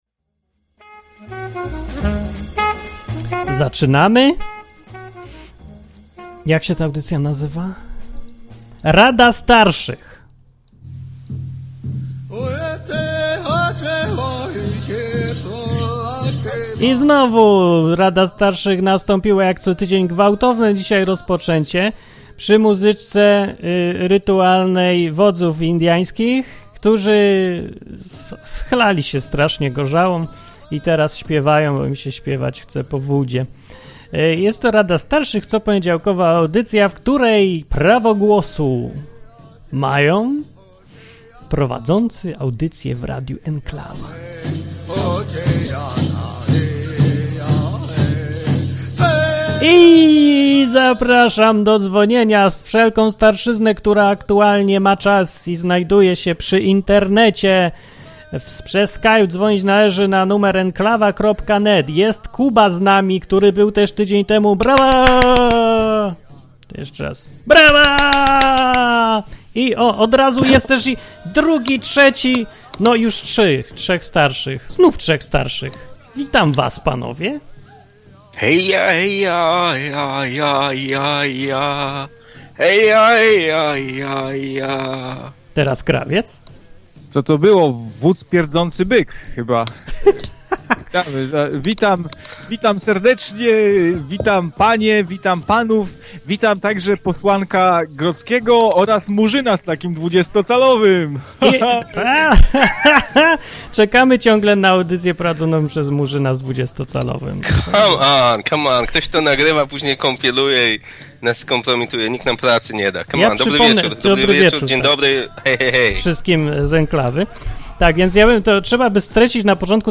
Co tydzień w poniedziałek prowadzący programy w Enklawie zbierają się, aby udzielać słuchaczom rad.